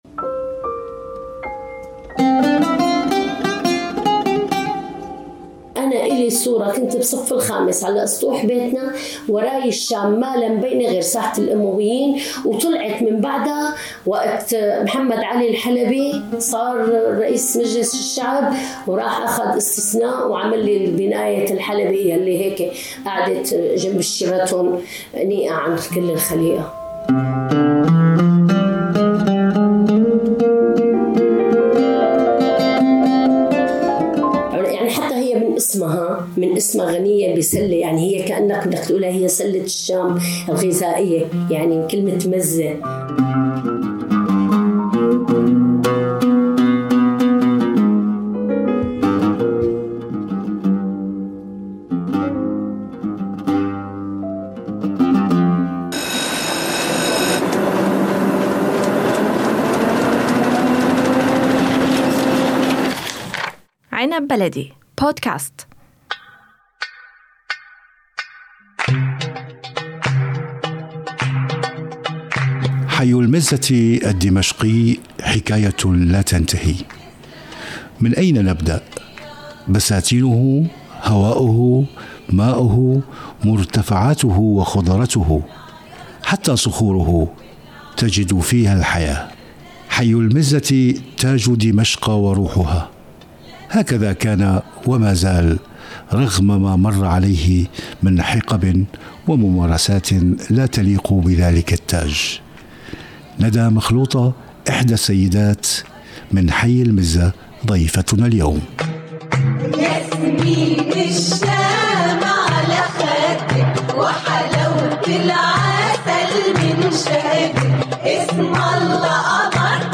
سيدة من حي المزة، ضيفتنا اليوم.